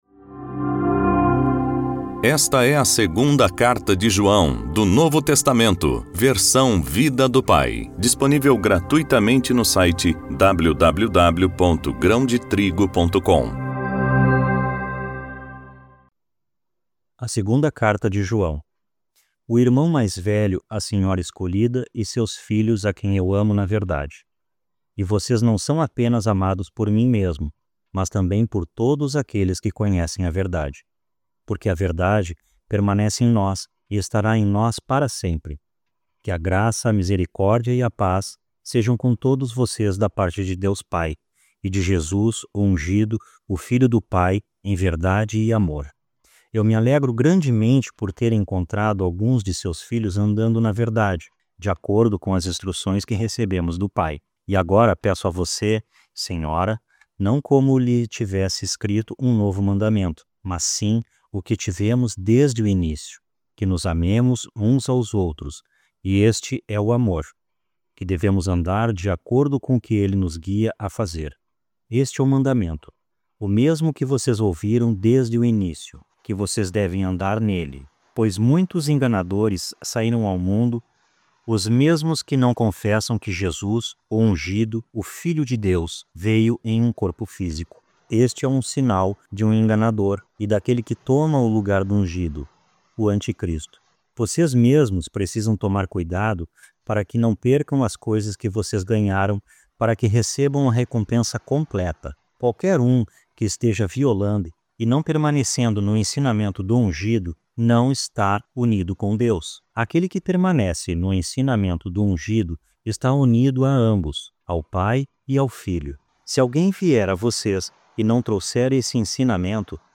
voz-audiobook-novo-testamento-vida-do-pai-segunda-joao-capitulo-1.mp3